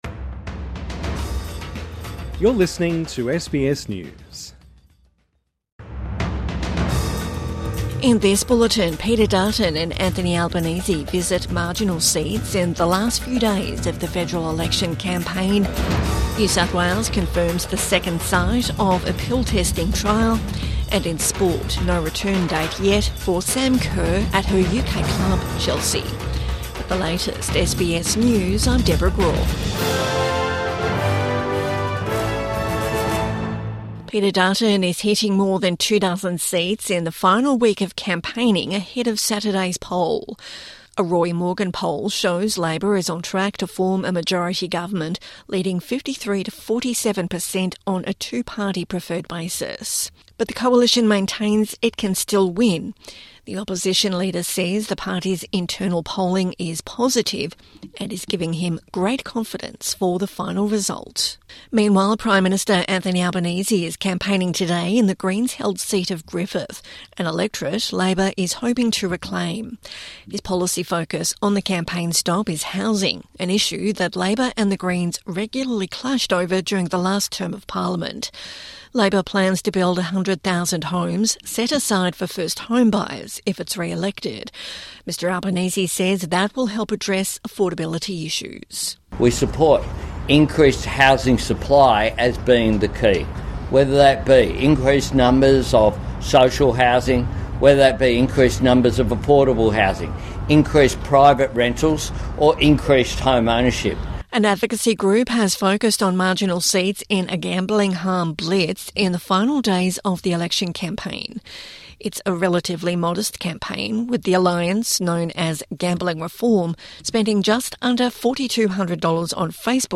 A bulletin of the day’s top stories from SBS News.